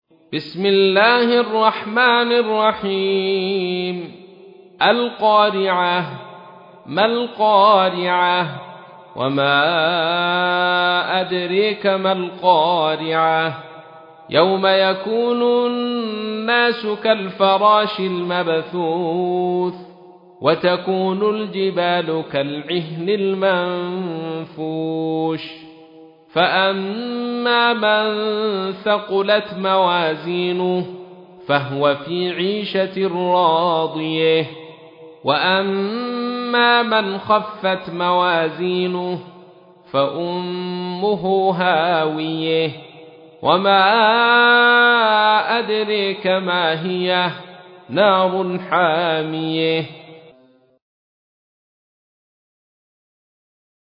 تحميل : 101. سورة القارعة / القارئ عبد الرشيد صوفي / القرآن الكريم / موقع يا حسين